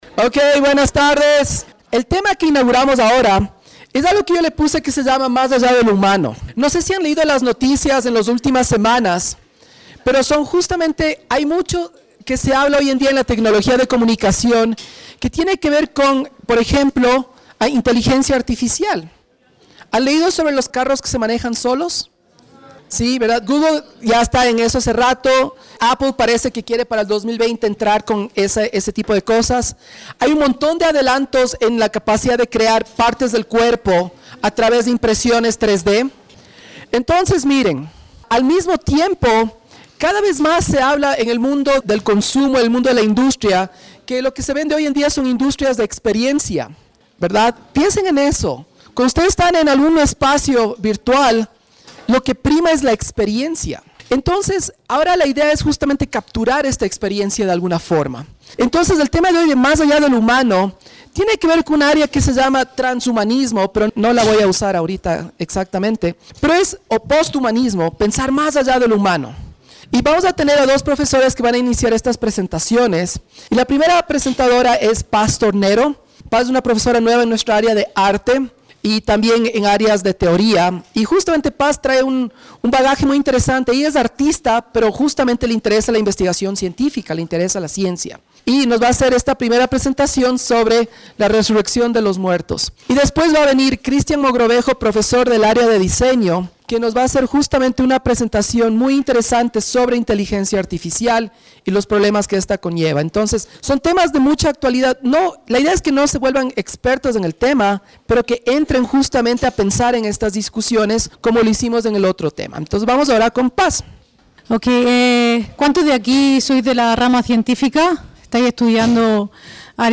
coloquios